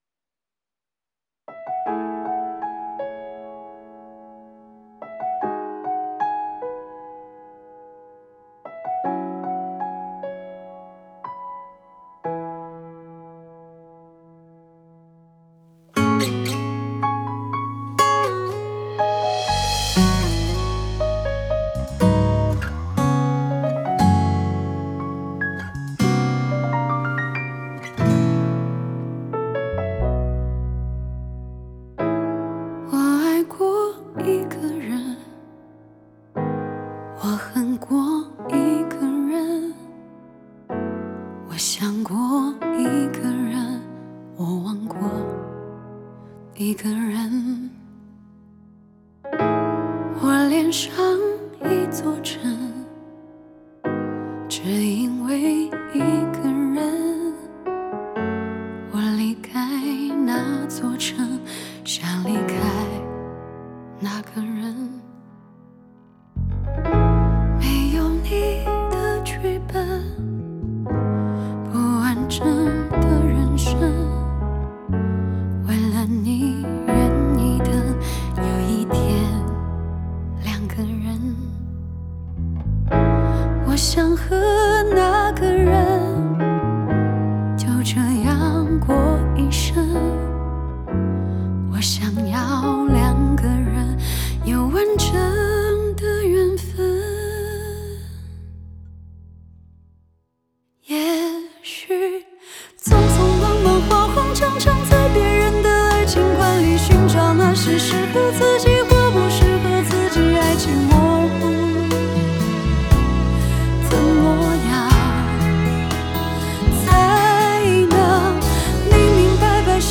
Ps：在线试听为压缩音质节选，体验无损音质请下载完整版
吉他
贝斯
小提琴